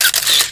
camera03.mp3